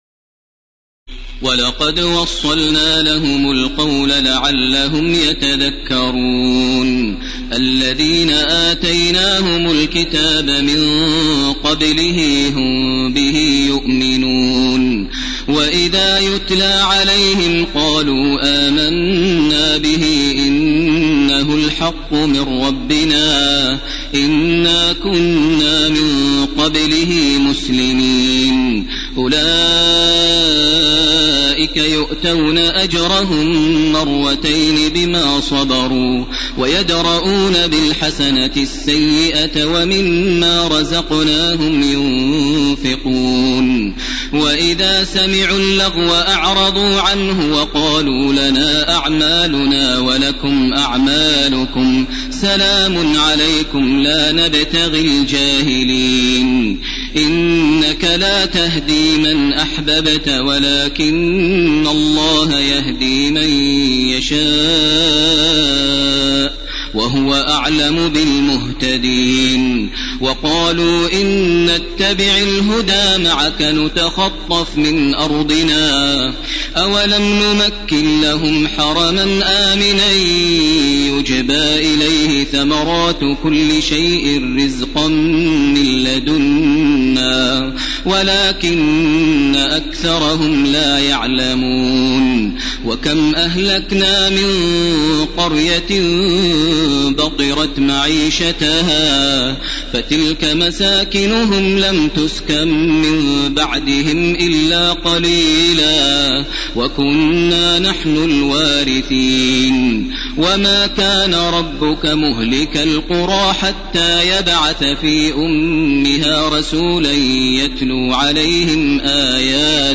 تراويح الليلة العشرون رمضان 1431هـ من سورتي القصص (51-88) والعنكبوت (1-45) Taraweeh 20 st night Ramadan 1431H from Surah Al-Qasas and Al-Ankaboot > تراويح الحرم المكي عام 1431 🕋 > التراويح - تلاوات الحرمين